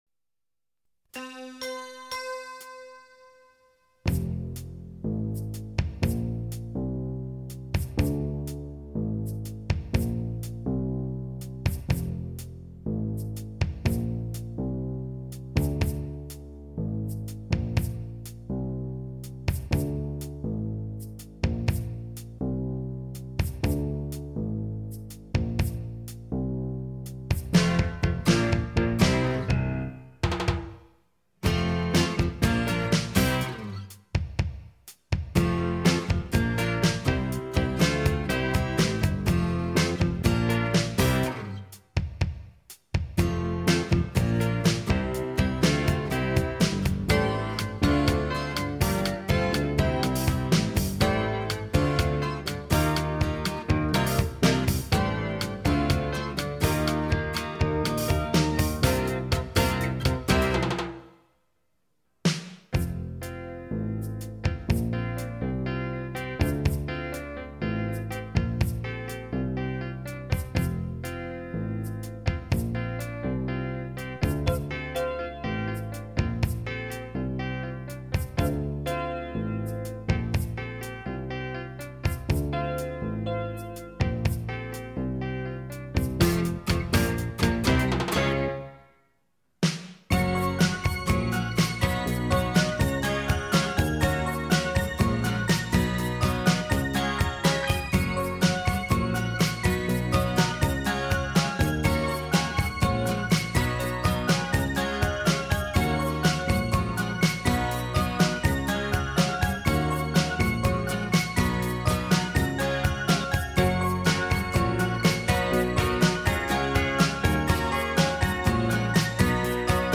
fun, upbeat goodbye song
Download Karaoke Track (Free MP3)